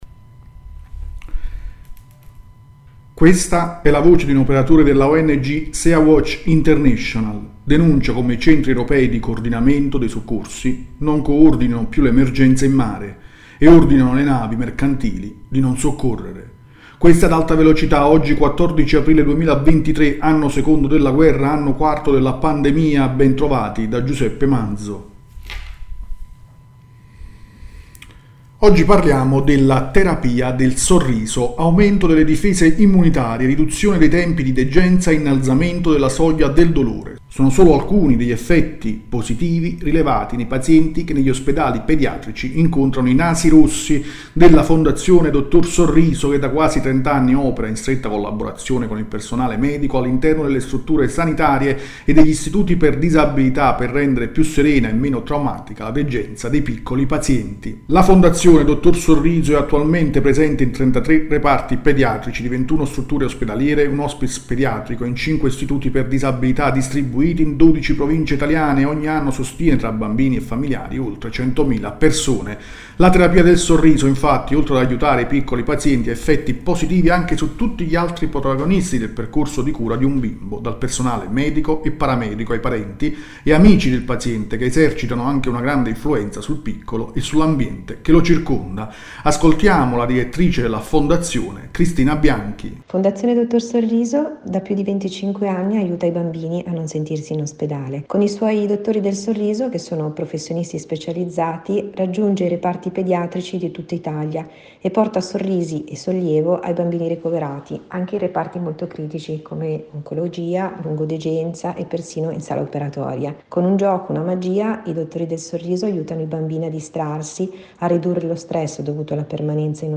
Questa è la voce di un operatore della Ong Sea Watch international: denuncia come  i centri europei di coordinamento dei soccorsi non coordinano più le emergenze in mare e ordinano alle navi mercantili di non soccorrere .